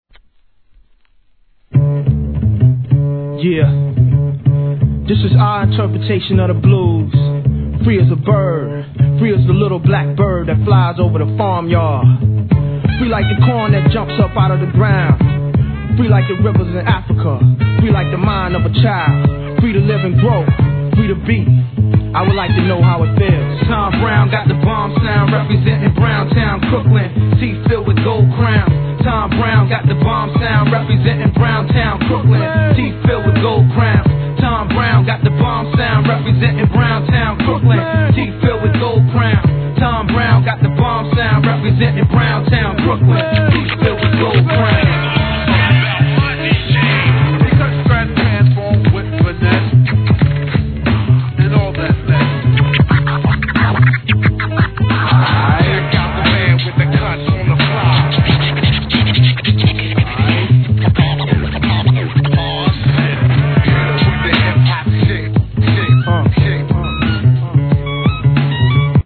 HIP HOP/R&B
ターンテ-ブリストによる2001年好コンピ!!!